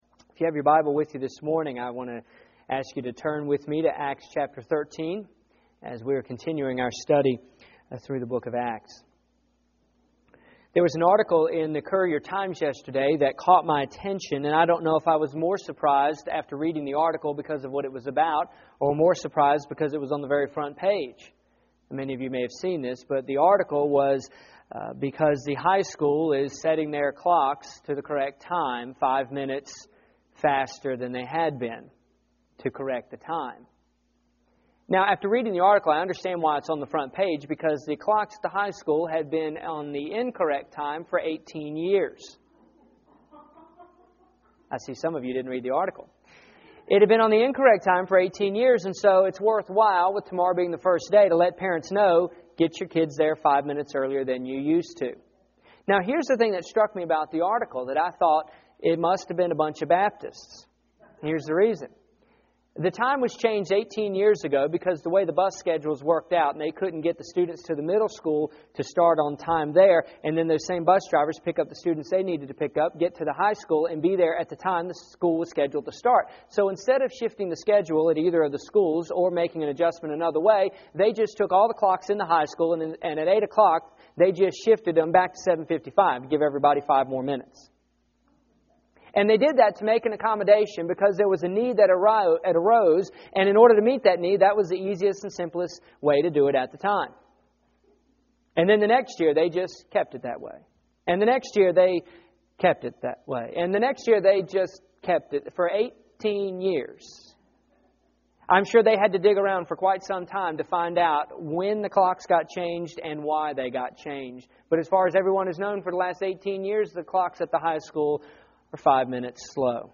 Here is my sermon from August 24, 2008.